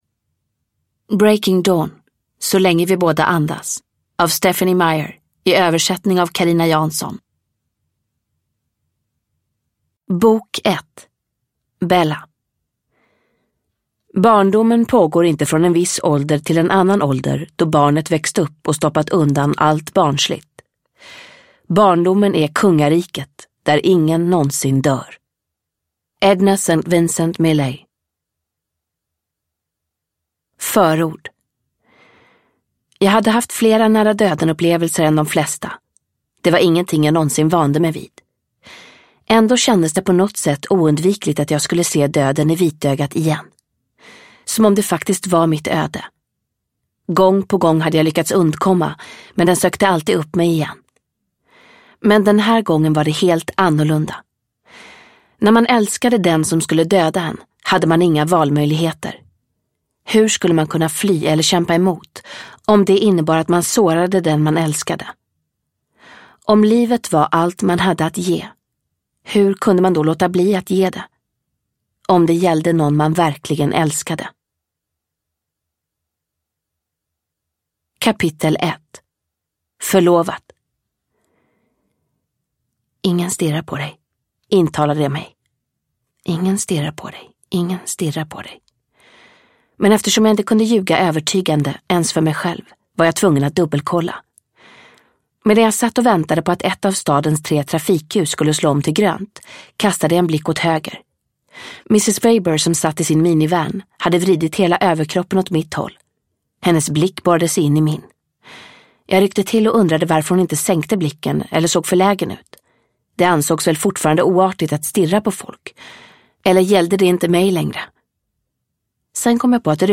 Så länge vi båda andas – Ljudbok